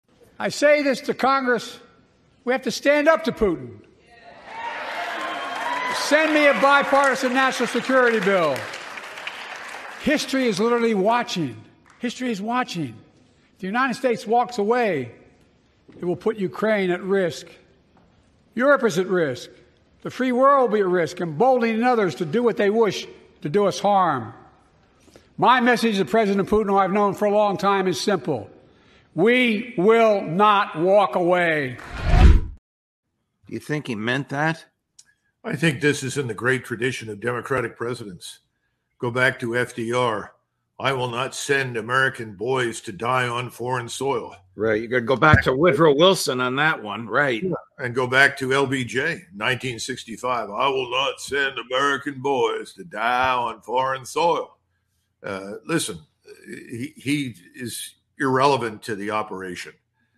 Col Douglas Macgregor: BEST IMPRESSION EVER: LBJ I will not… | Judge Napolitano – Judging Freedom
col-douglas-macgregor-best-impression-ever-lbj-i-will-not.mp3